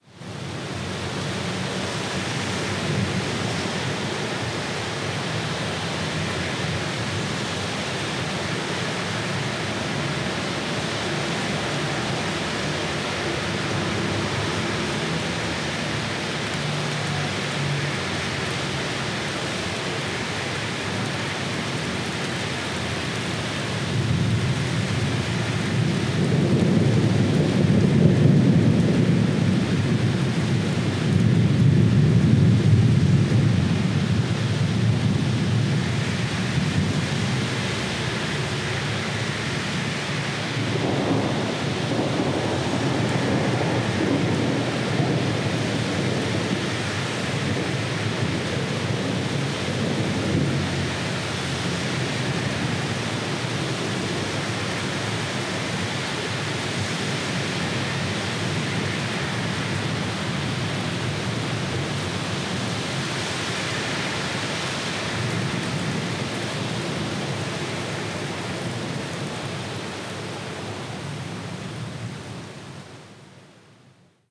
Ambiente de tormenta fuerte
trueno tronar tormenta diluviar electricidad llover lluvia